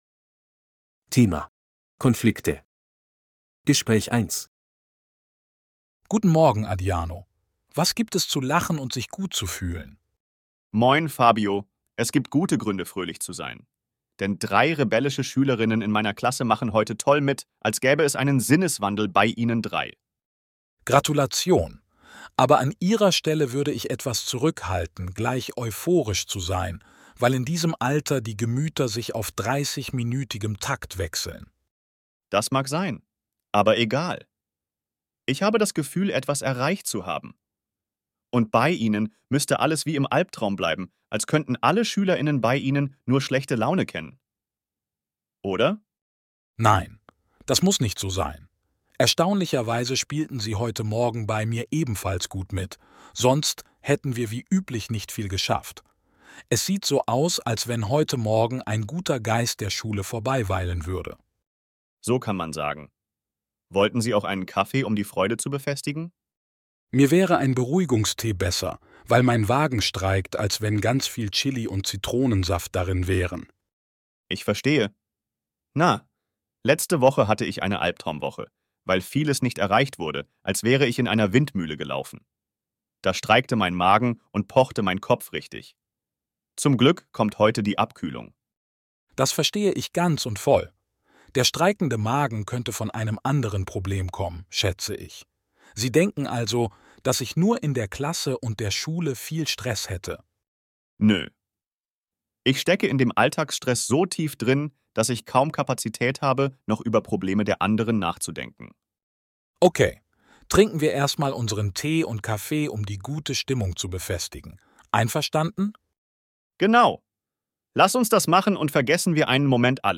Bài nghe hội thoại 1:
B1-Registeruebung-8-Konflikte-Gespraech-1.mp3